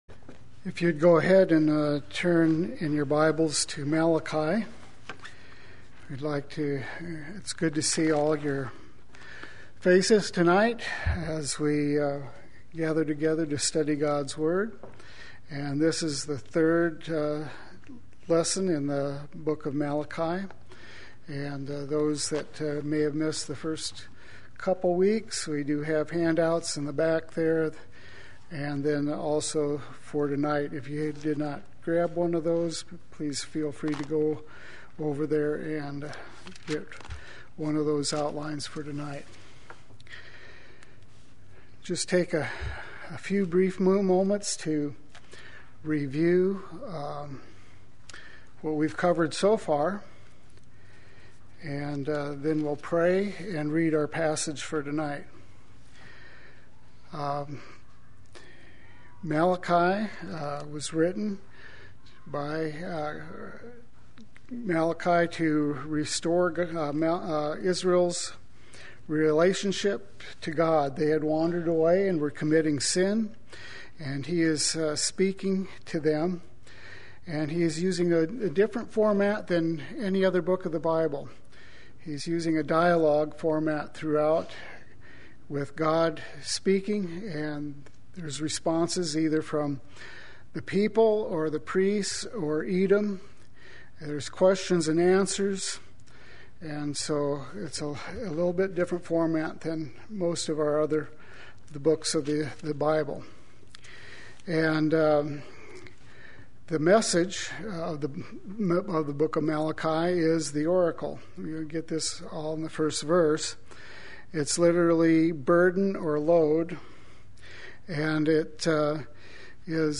Play Sermon Get HCF Teaching Automatically.
Part 3 Wednesday Worship